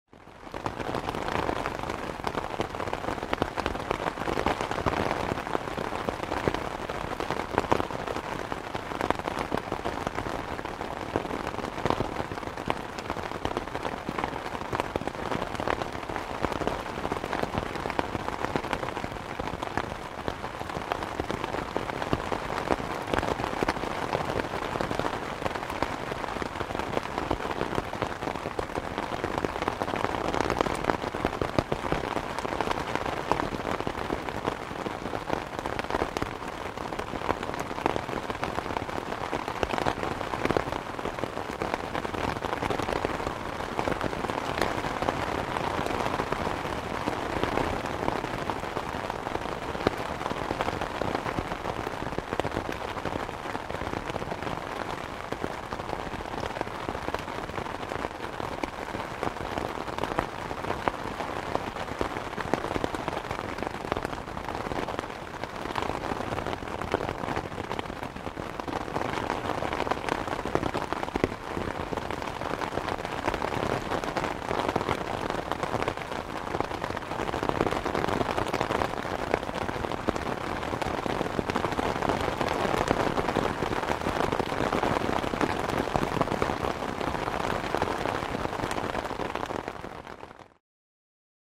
Звуки погоды
Дождь стучит по зонтику